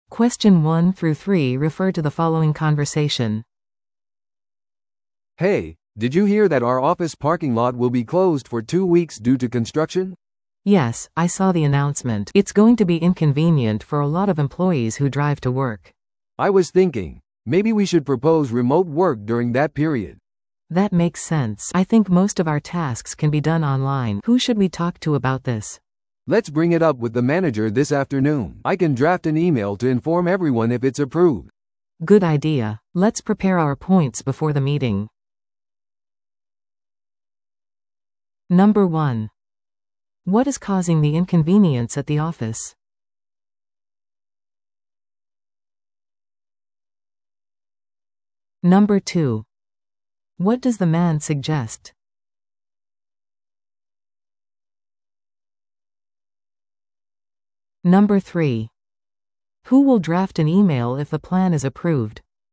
TOEICⓇ対策 Part 3｜リモートワーク提案に関する職場の会話 – 音声付き No.58